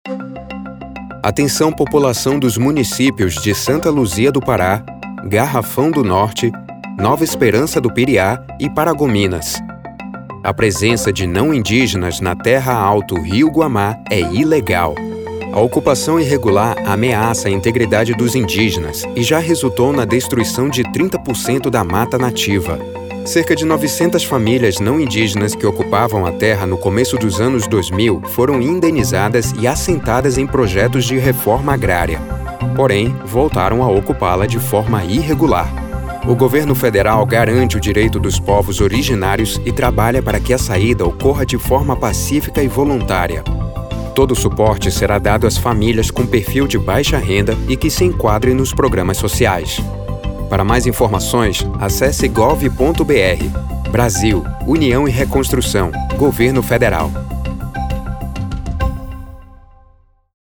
Informative